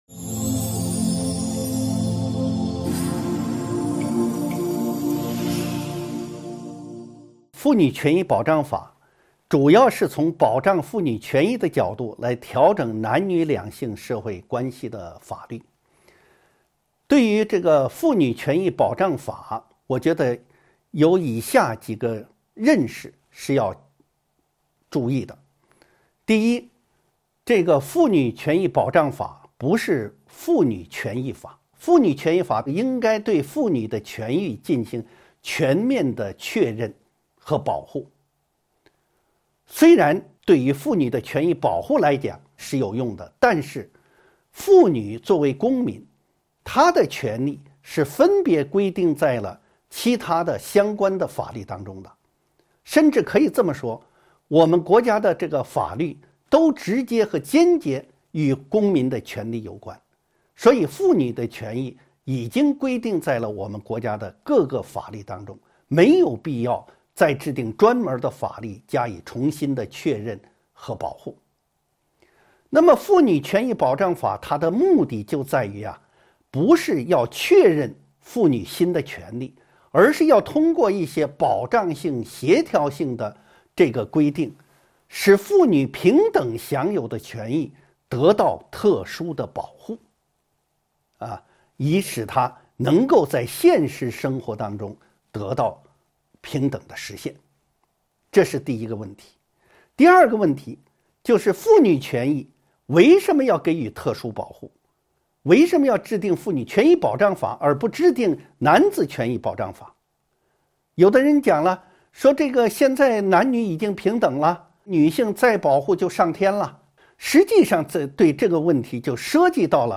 音频微课：《中华人民共和国妇女权益保障法》1.妇女权益保障法的制定和修改